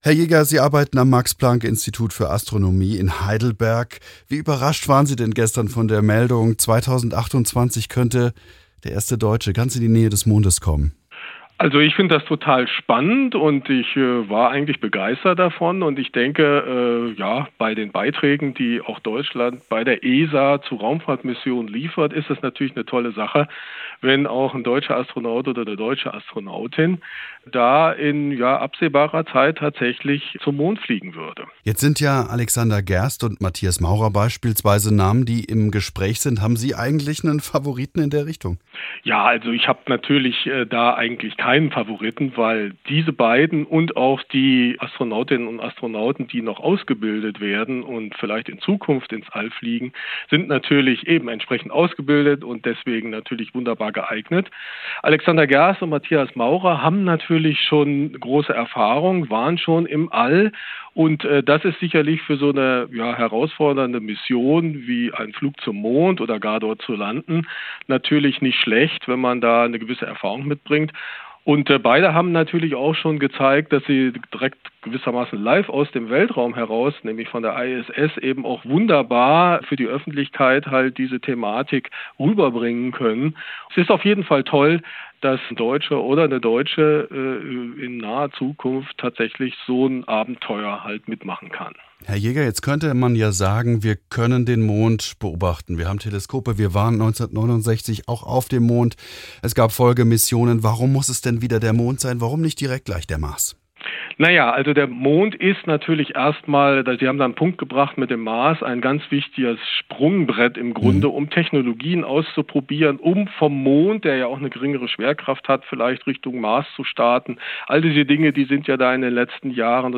Die Missionen, sagt er im Interview mit SWR Aktuell, haben aber auch einen echten wissenschaftlichen Nutzen.